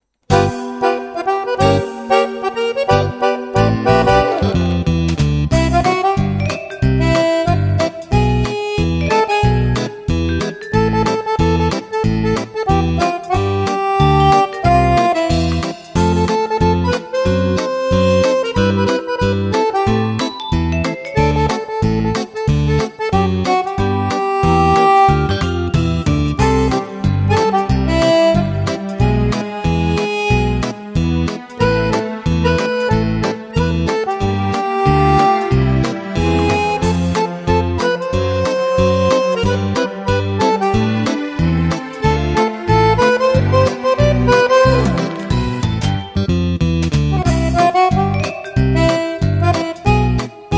15 ballabili per Fisarmonica